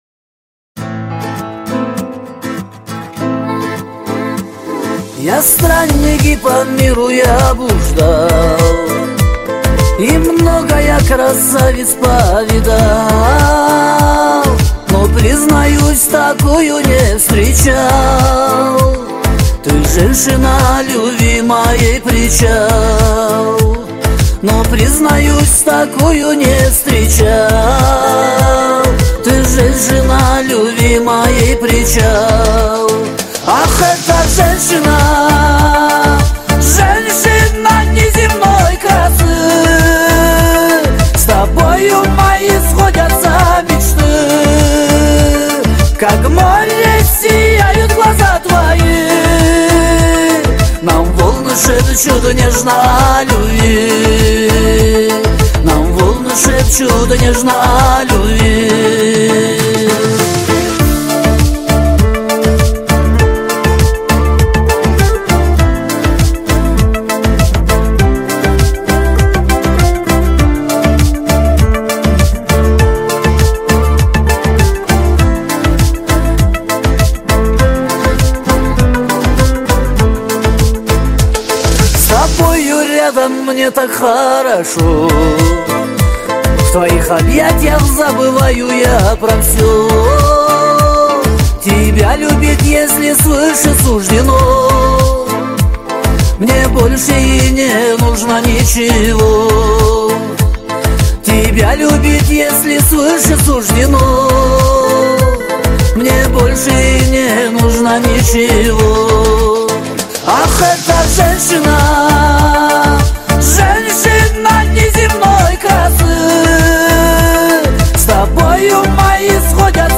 Кавказские песни